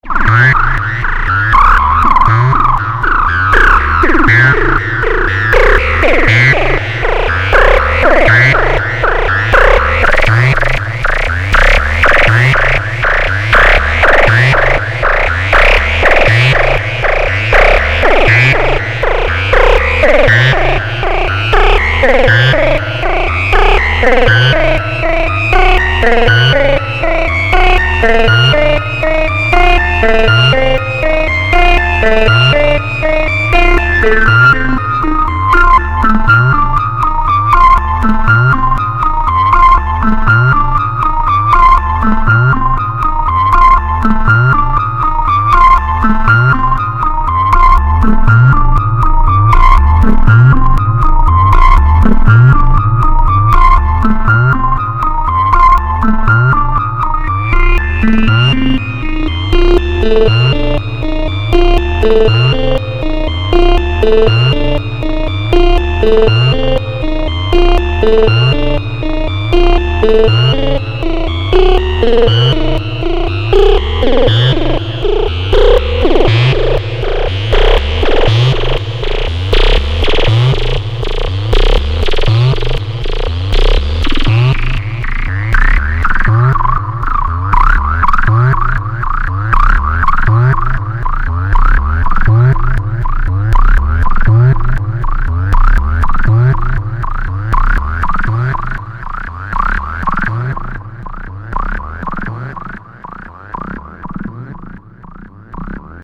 Мнда, как-бы в модулярщики не записаться - дорогое хобби :-/ Кусочек прицепил просто так, ничего особенного - просто кручу, но может кому интересно будет. Вложения System1mTest.mp3 System1mTest.mp3 2 MB · Просмотры: 438